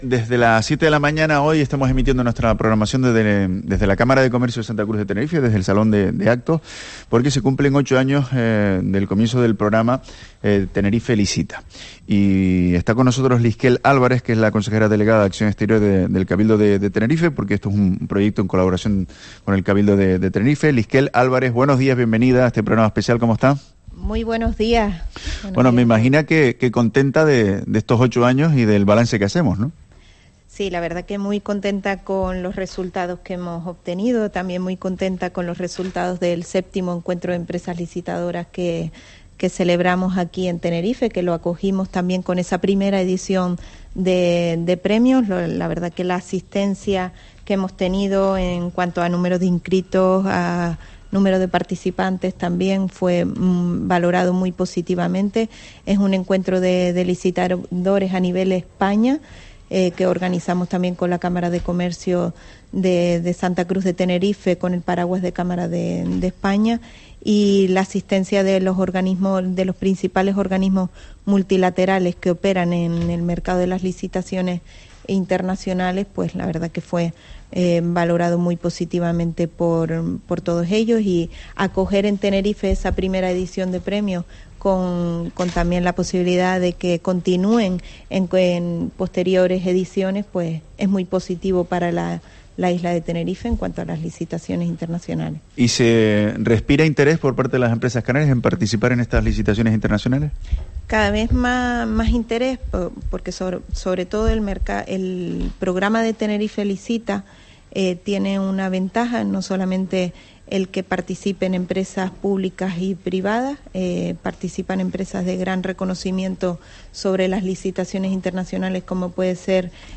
Liskel Álvarez, consejera delegada de Acción Exterior del Cabildo de Tenerife
Es una iniciativa conjunta del Cabildo Insular de Tenerife y de la Cámara de Comercio de Santa Cruz de Tenerife y con motivo de esta efeméride la cadena COPE en Canarias ha realizado su programación regional desde ésta última.